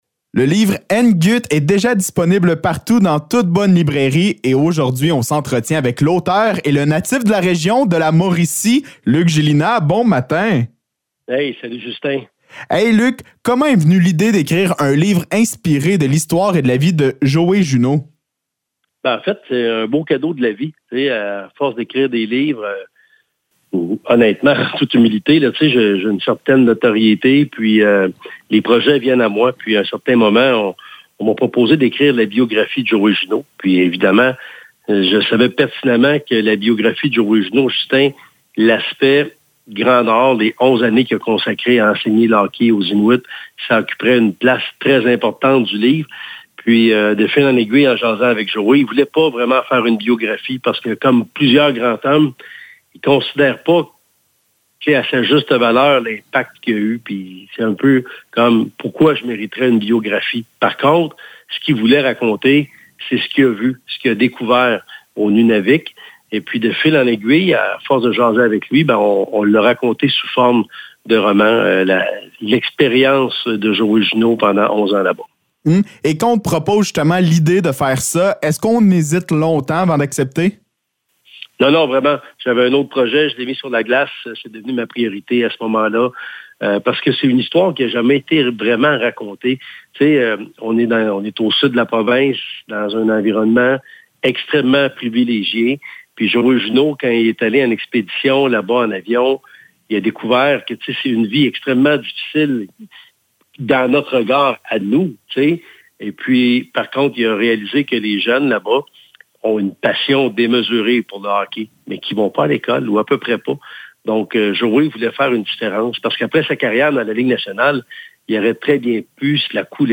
Entrevue avec Luc Gélinas